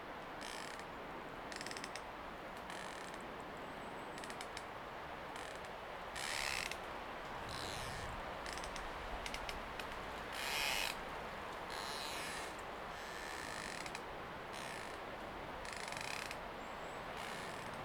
Wind_Trees.ogg